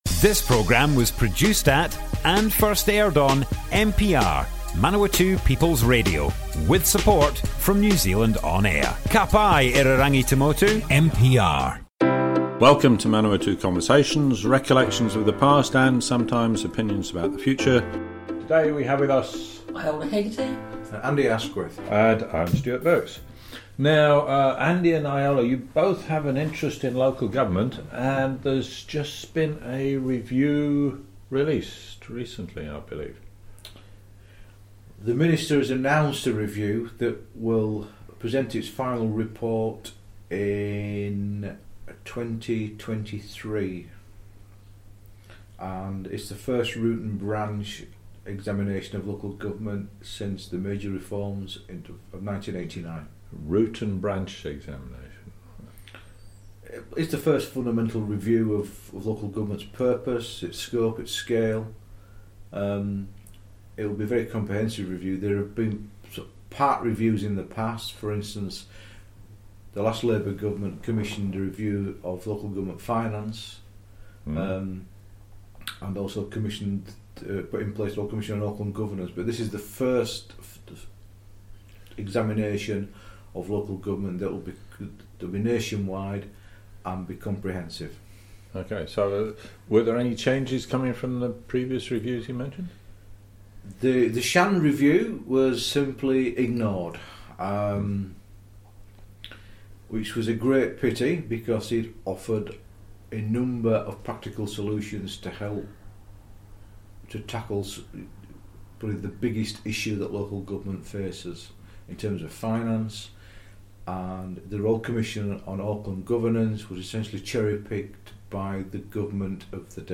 Manawatu Conversations Object type Audio More Info → Description Broadcast on Manawatu People's Radio, 13th July 2021.
oral history